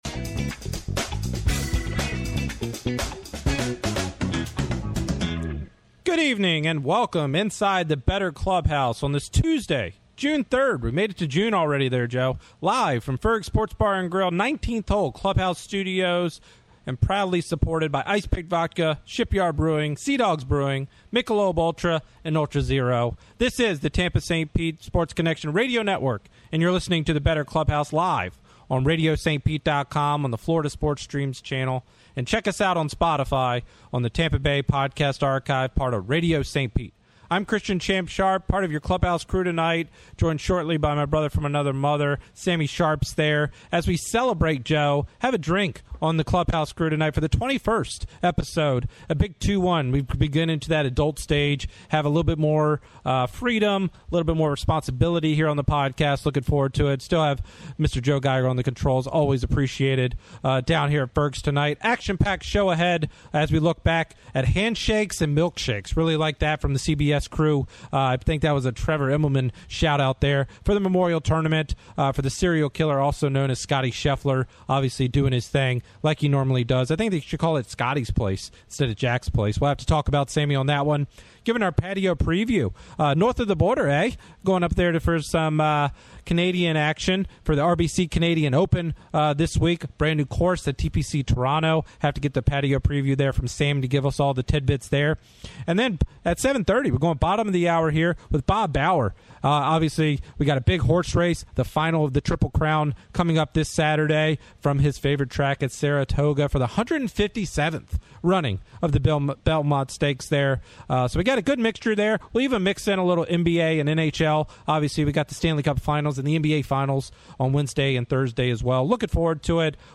S2 Ep770: "The Bettor Clubhouse" 6-3-25; Live from Ferg's Tuesdays 7pm ET